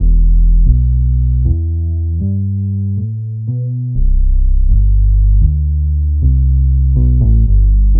Bass 36.wav